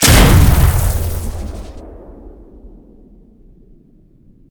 pistol2.ogg